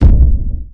sound / steps / giant2.wav
giant2.wav